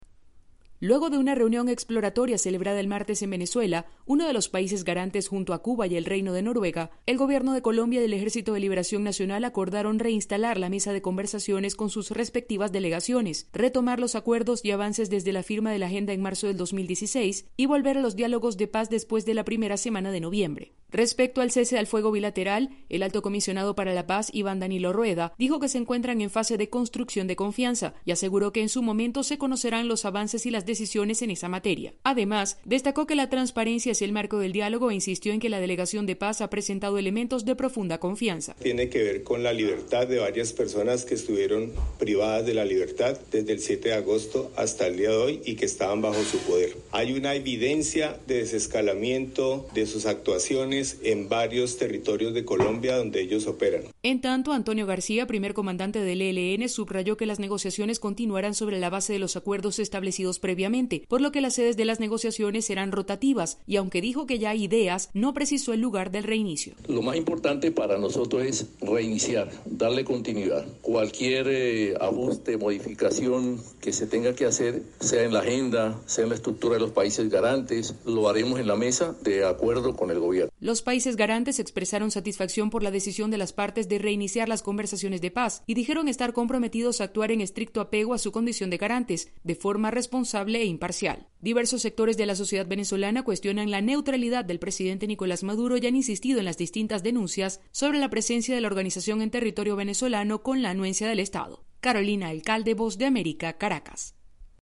En Caracas y con Venezuela como país garante, Colombia y la guerrilla del ELN acordaron reanudar los diálogos de paz. Desde Caracas informa la corresponsal de la Voz de América